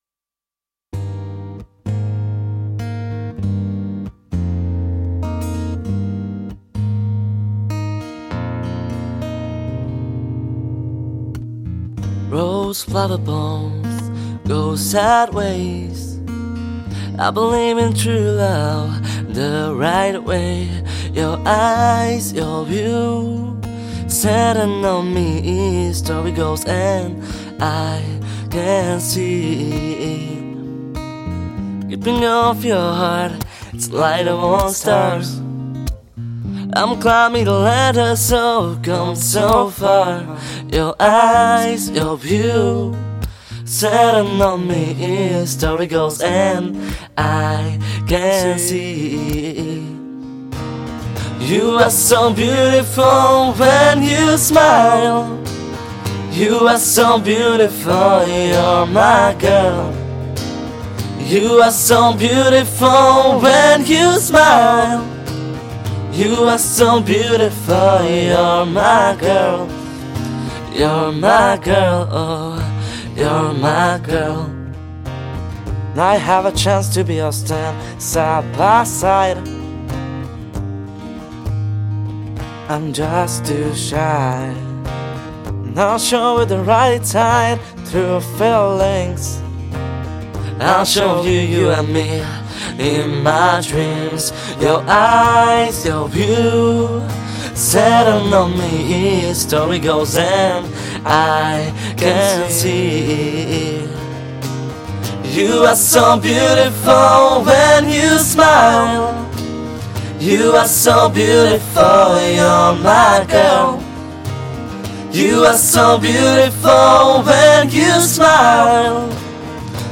Žánr: Pop